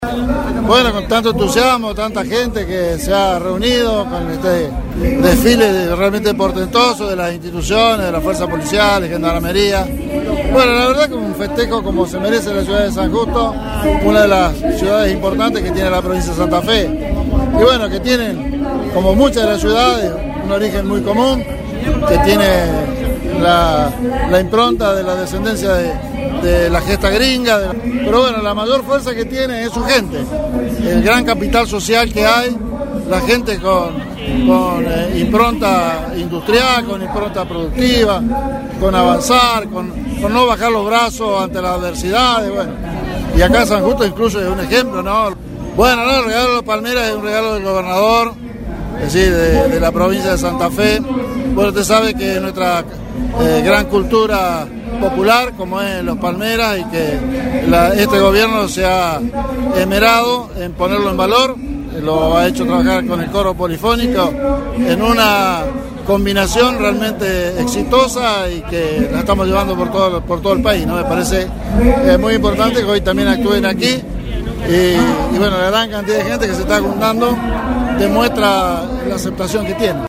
«Es un regalo del Gobernador, de la provincia de Santa Fe, es parte de nuestra gran cultura popular. Este gobierno se ha esmerado de ponerlos en valor a Los Palmeras llevándolos a tocar a distintos puntos de la provincia»  dijo Carlos Fascendini, vice gobernador, quien fue el representante del gobierno provincial en el acto.